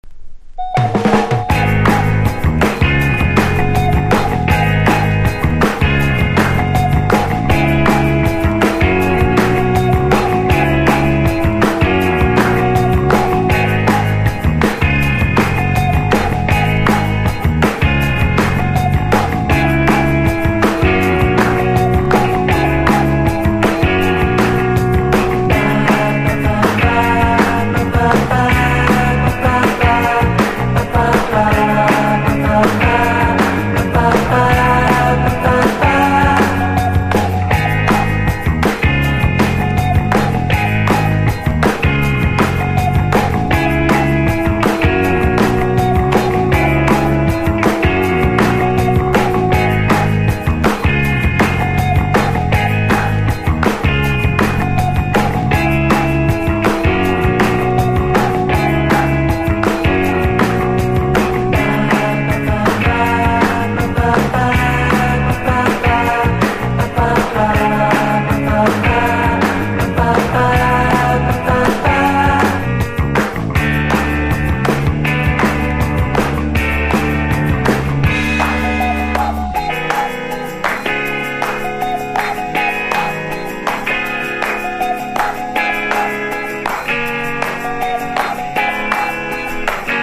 LOUNGE